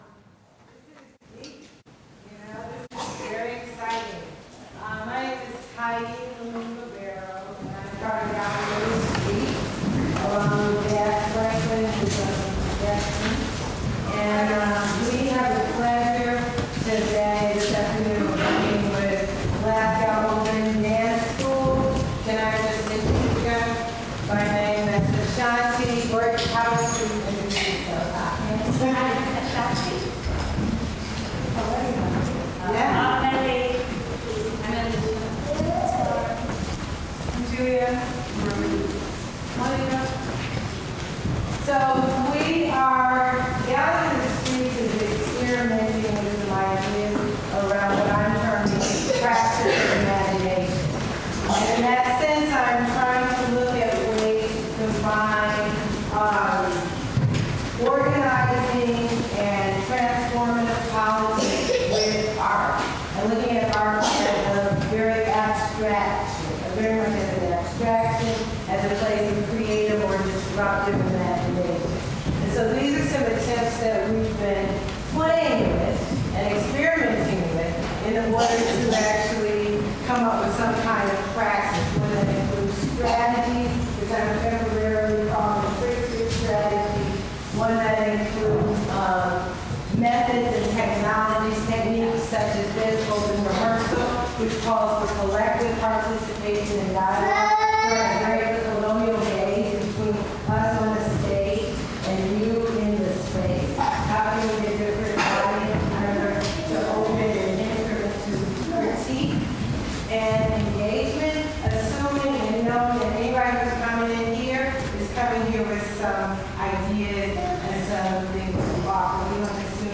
[b]reach: The Fugitive Chronicles – an open rehearsal